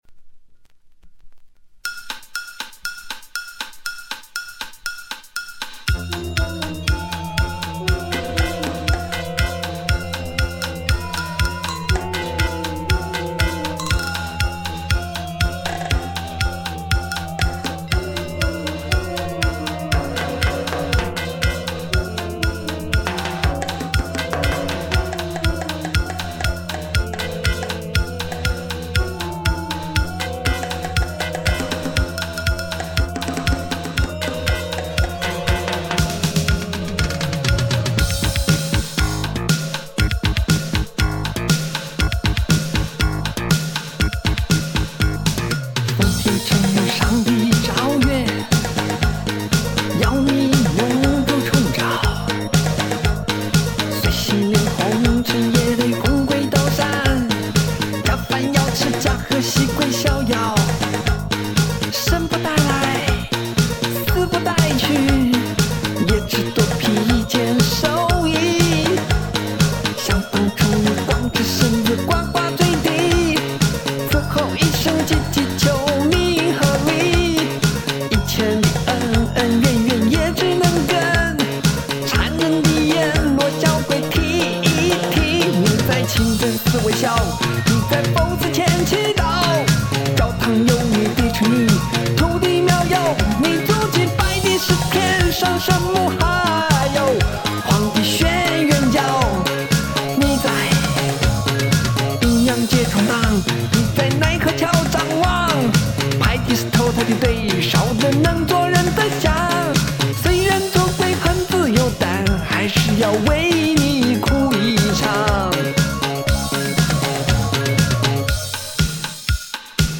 一张与众不同、风格迥异、不顾一切的实验性作品。